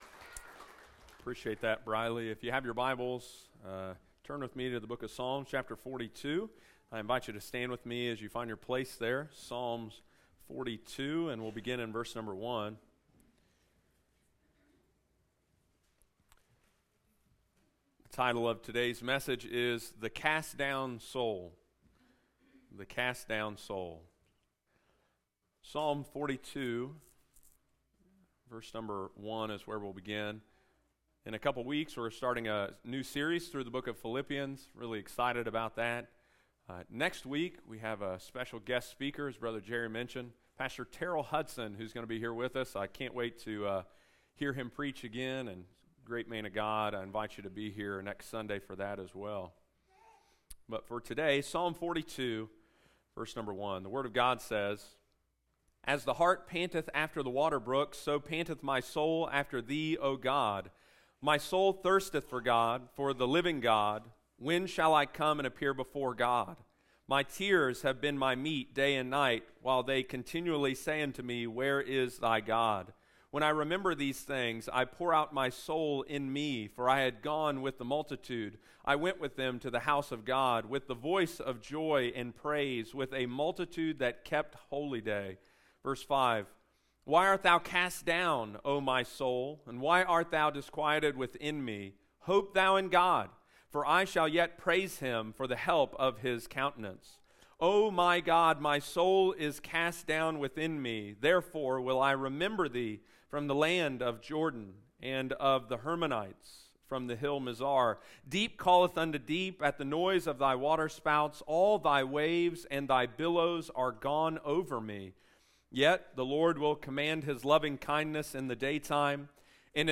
Sunday morning, July 2, 2023.